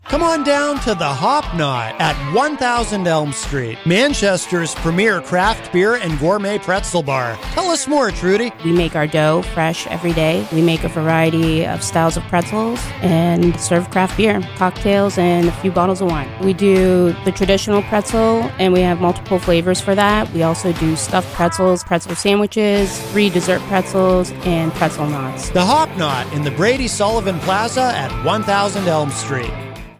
Hop Knot commercial spot
Category: Radio   Right: Personal